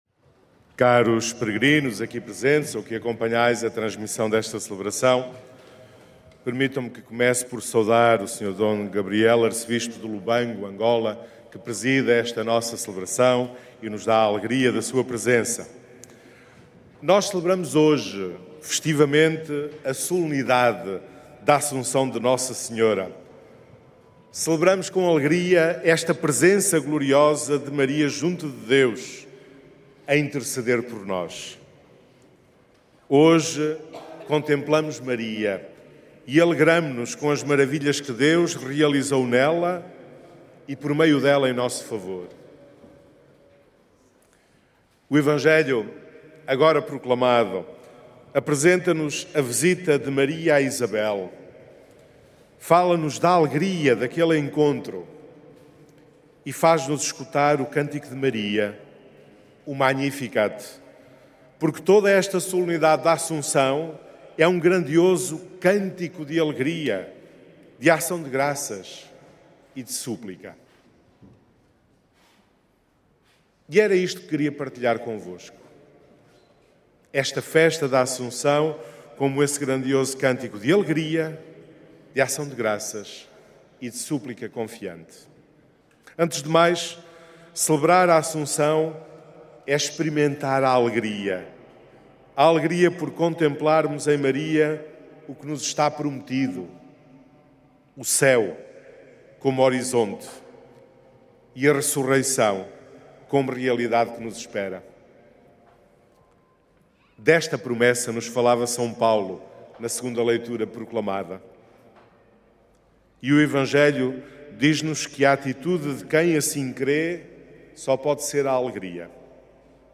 Cerca de 50 mil peregrinos participaram na missa que celebrou a solenidade da Assunção de Nossa Senhora, nesta sexta-feira.
Áudio da homilia